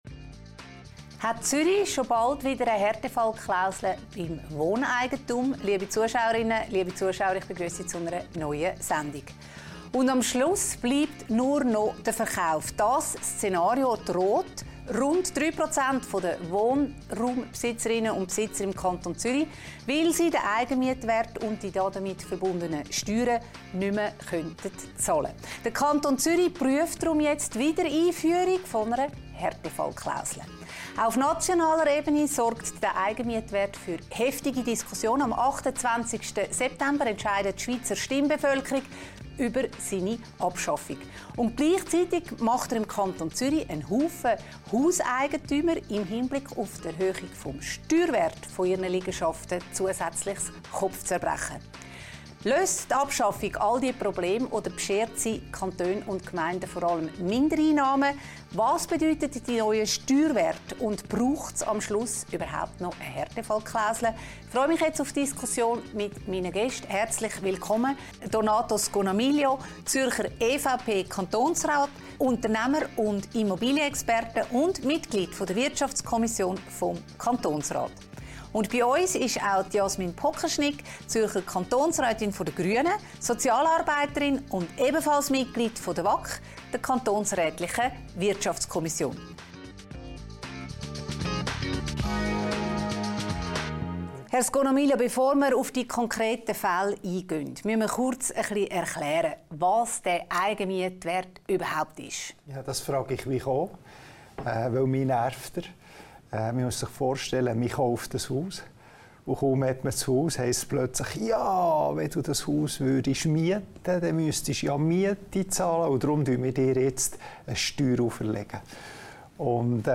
im Gespräch mit den Zürcher Kantonsräten Jasmin Pokerschnig, Grüne und Donato Scognamiglio, EVP über die Wiedereinführung einer Härtefallklausel für Wohneigentum im Kanton Zürich